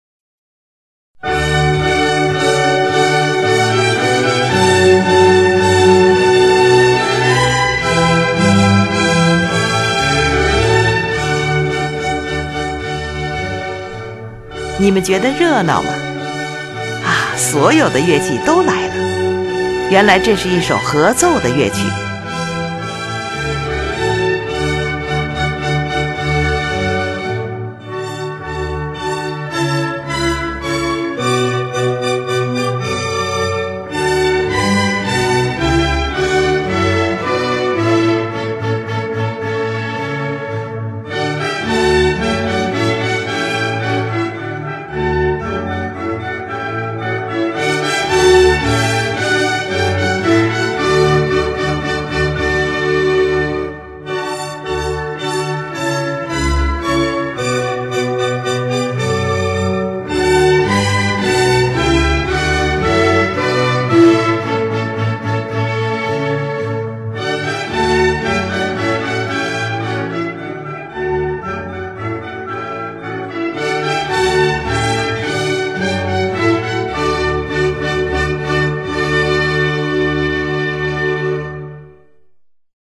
in F Major
是一部管弦乐组曲
乐器使用了小提琴、低音提琴、日耳曼横笛、法兰西横笛、双簧管、圆号、小号等